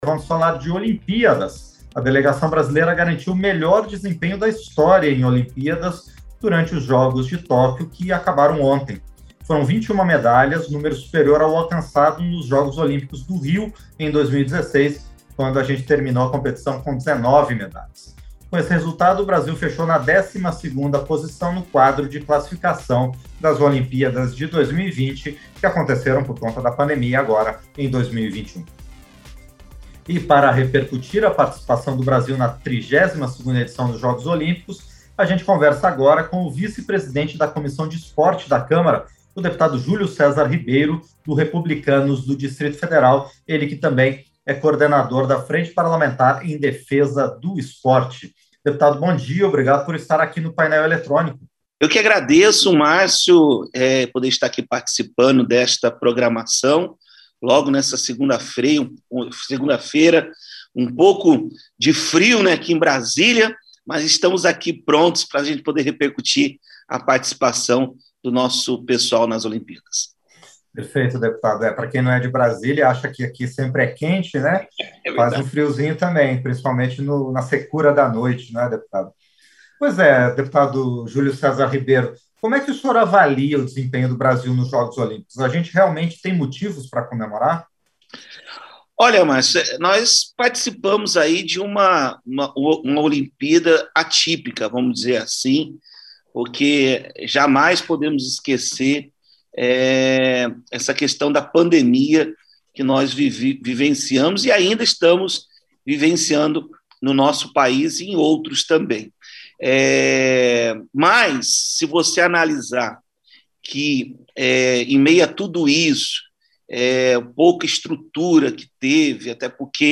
Entrevista - Dep. Júlio César Ribeiro (REP-DF)